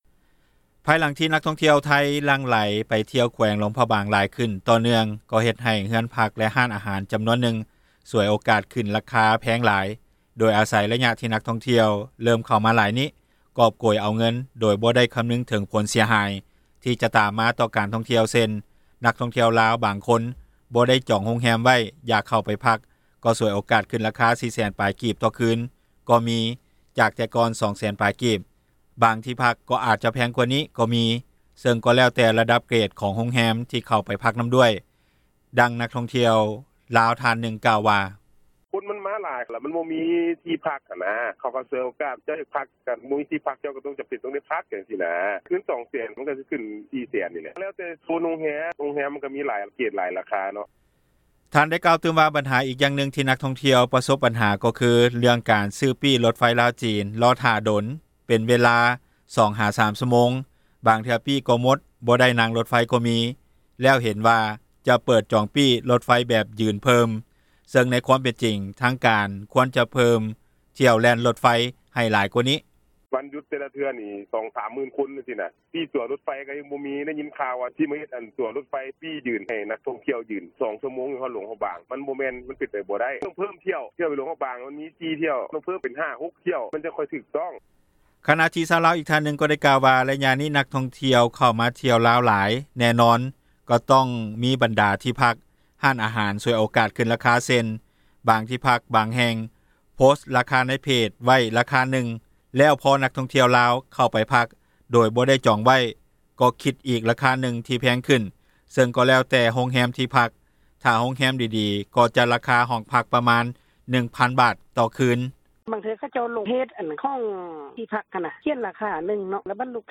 ດັ່ງນັກທ່ອງທ່ຽວລາວ ທ່ານນຶ່ງ ກ່າວວ່າ: